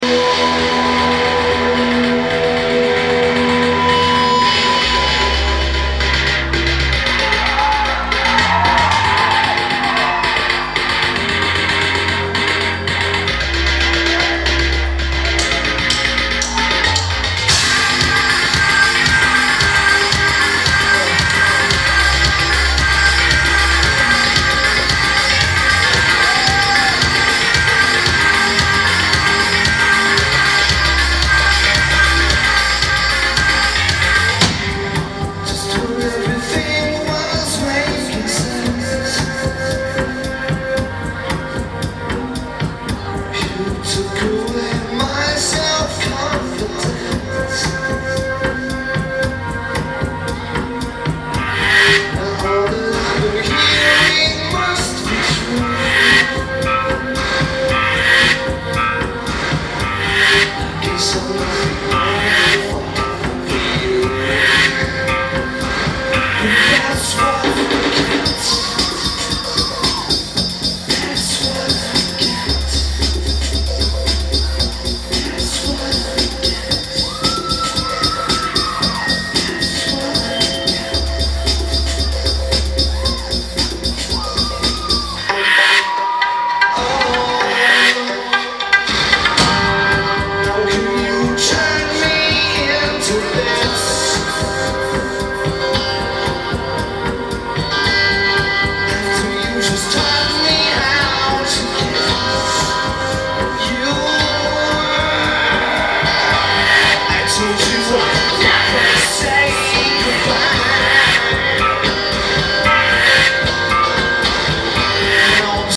Cabaret Metro
Lineage: Audio - AUD (Sony ECM-909 + Sony WM-D6)